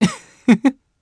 Arch-Vox-Laugh_jp.wav